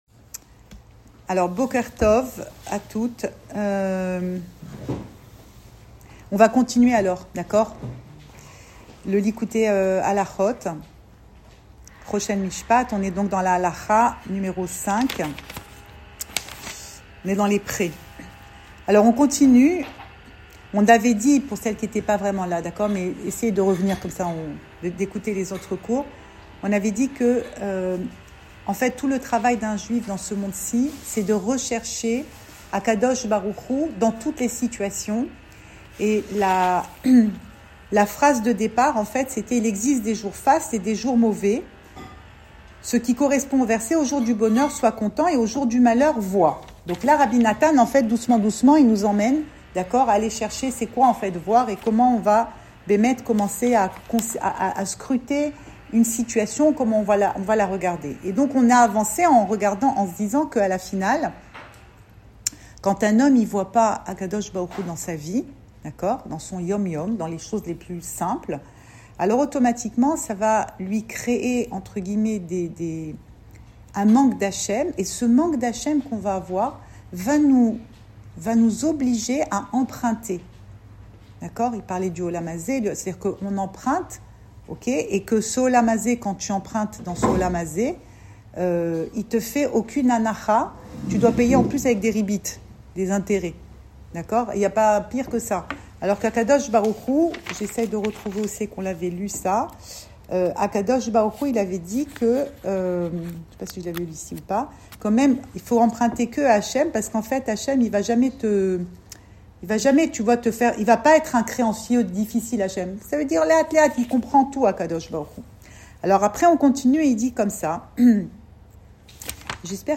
Cours audio Le coin des femmes Le fil de l'info Pensée Breslev - 7 mai 2025 8 mai 2025 Vivre avec rien. Enregistré à Tel Aviv